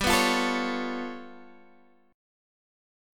G9b5 chord